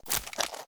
creaking_step5.ogg